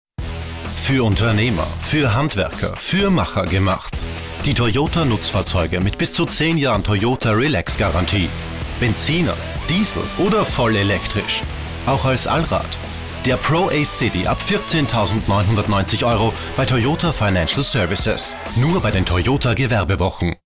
Professioneller Sprecher (Deutsch) aus Wien.
Sprechprobe: Werbung (Muttersprache):